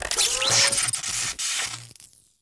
powerup.ogg